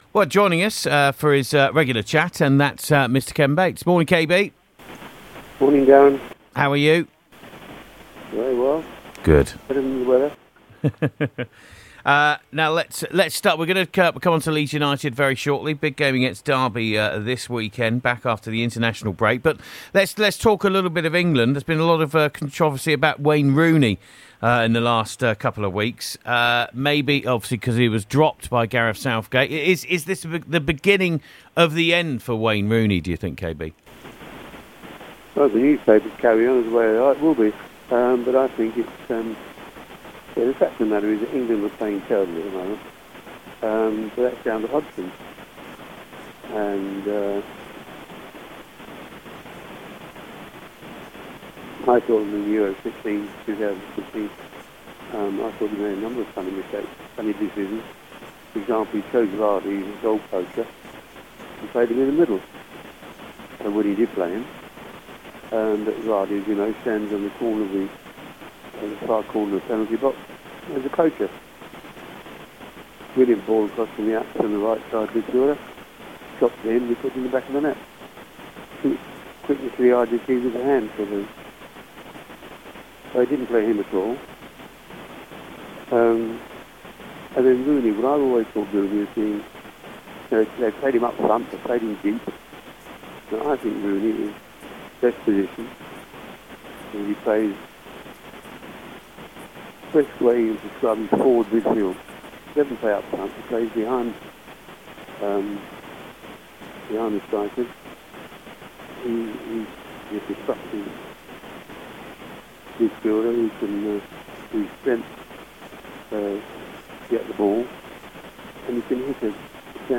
The Ken Bates Interview